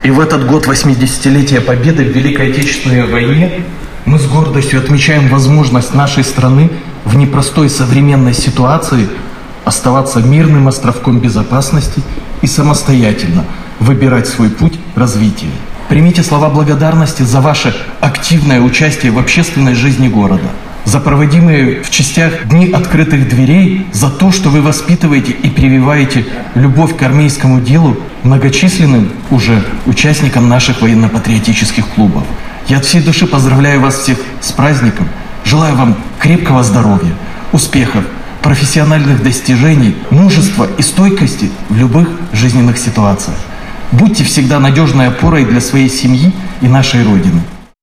В городском Доме культуры состоялась торжественное мероприятие.
Это праздник тех, кто военное дело выбрал делом своей жизни, людей в погонах, а самое главное всех патриотов, кто готов к реальным действиям при необходимости защищать интересы нашей страны, — подчеркнул, обращаясь к присутствующим в зале, глава города Максим Антонюк.